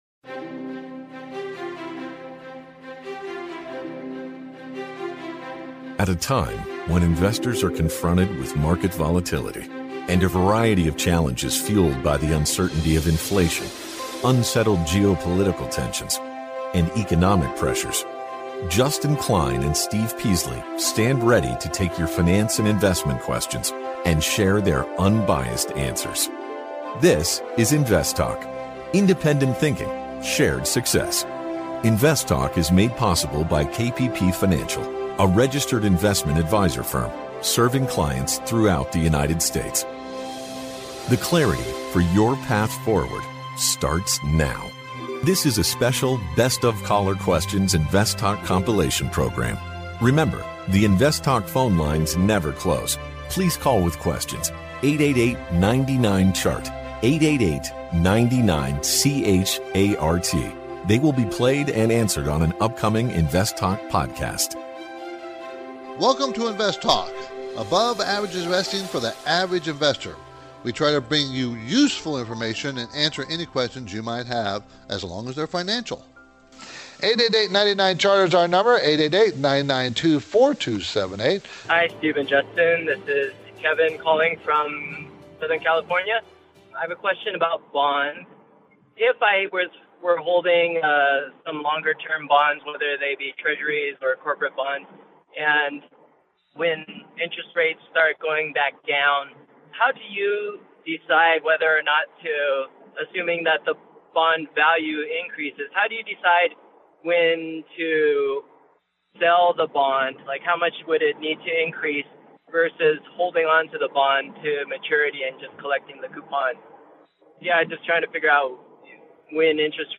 field a variety of finance and investment questions from callers across the United States and around the world.